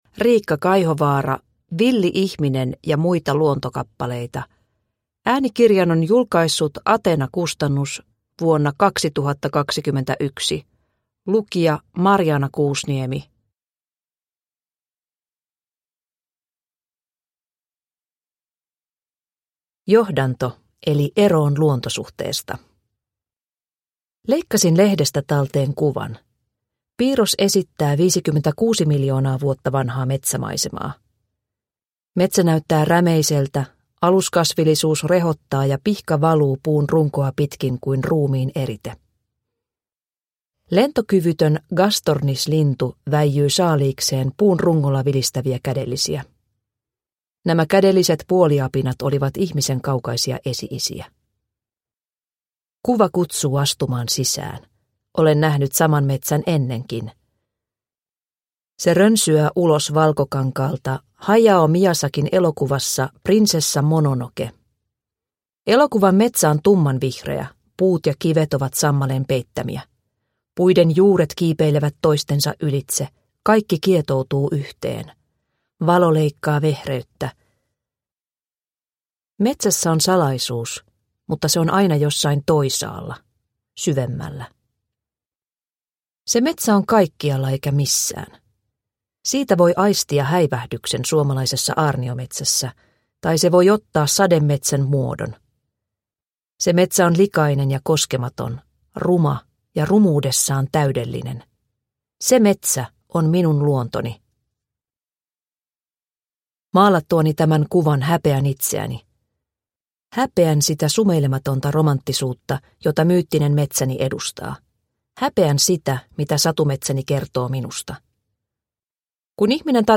Villi ihminen – Ljudbok – Laddas ner